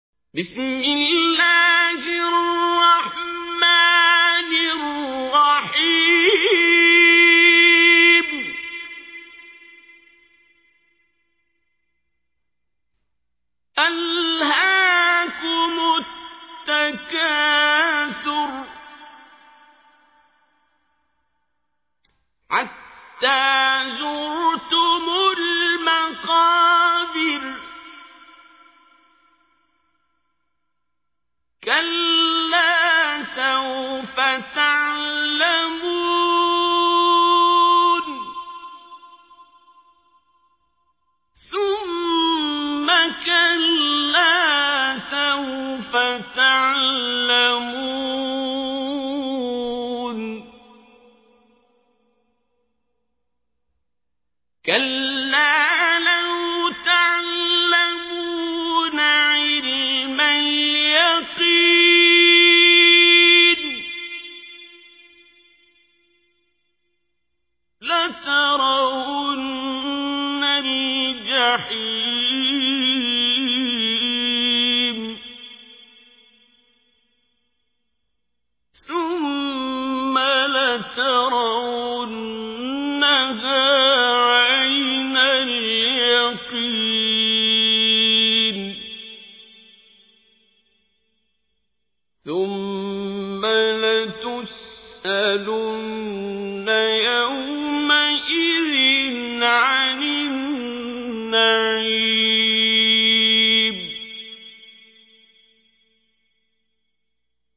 تحميل : 102. سورة التكاثر / القارئ عبد الباسط عبد الصمد / القرآن الكريم / موقع يا حسين